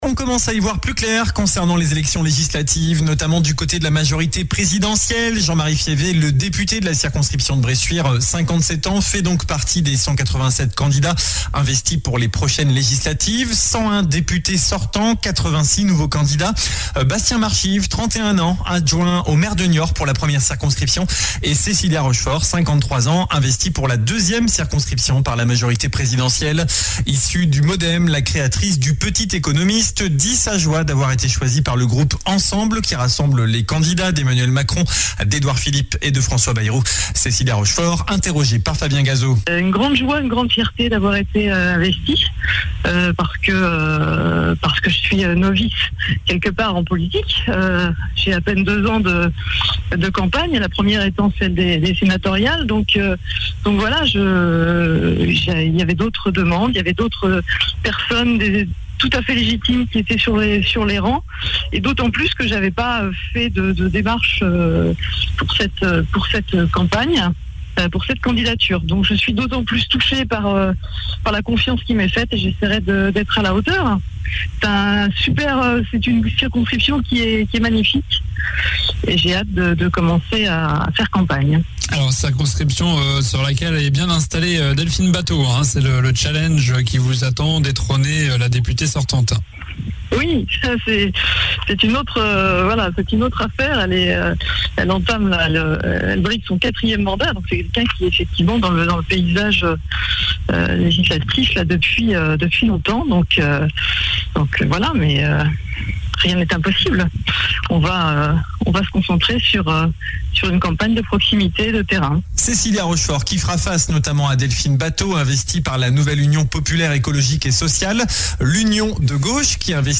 Journal du samedi 7 mai